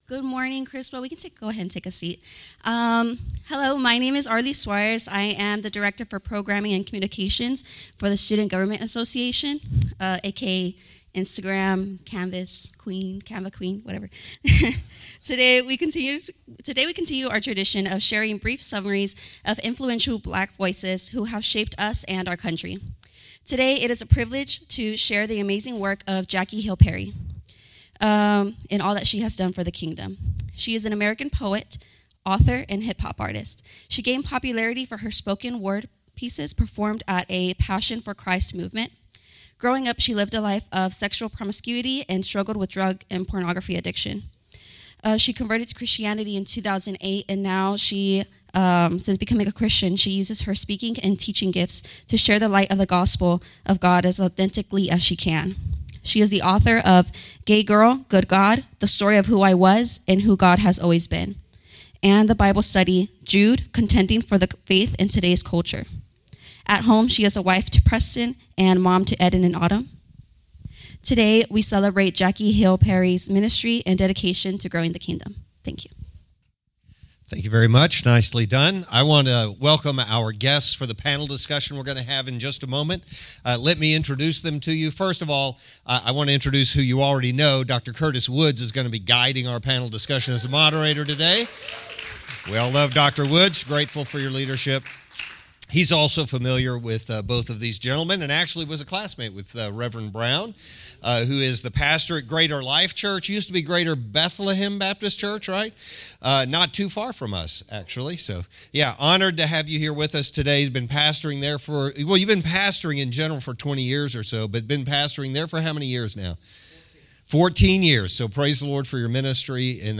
Criswell College Chapel Service. Black History Month Panel.